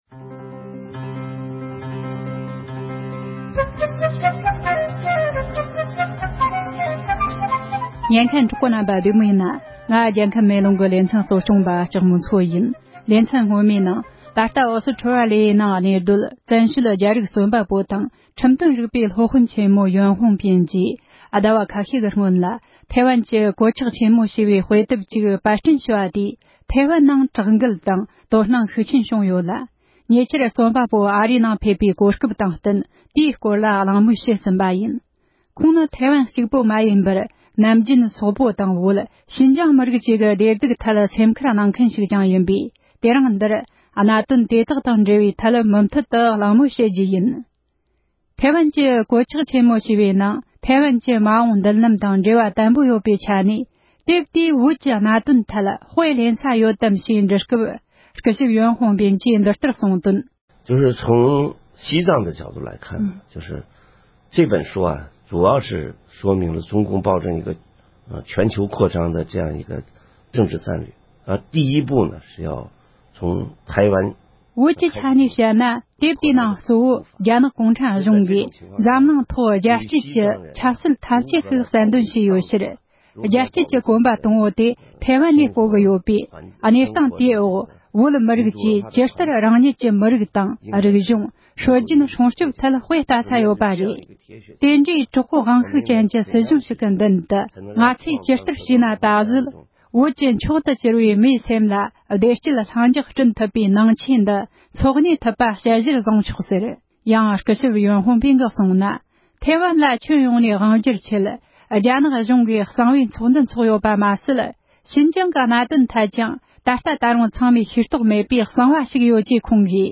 བོད་དང་ཤིན་ཅང་གནད་དོན་ཐད་གླེང་མོལ།